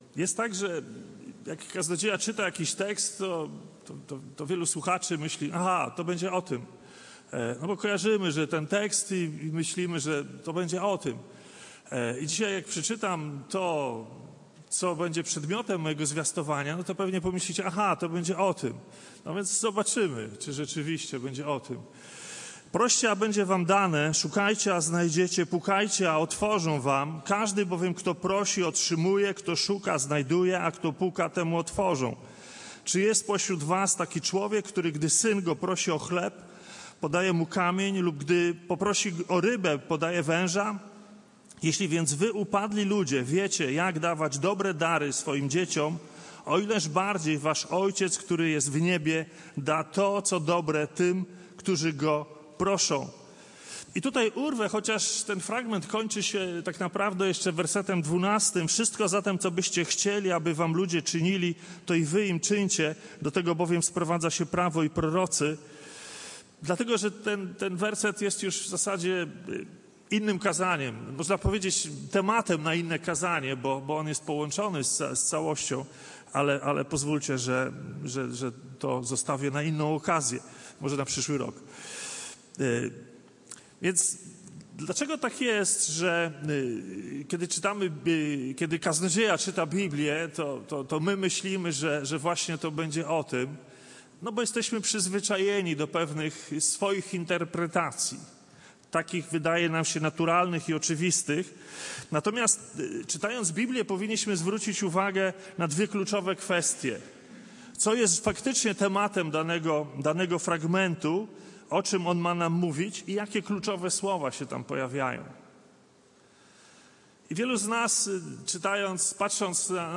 Passage: Ewangelia Mateusza 7, 7-11 Kazanie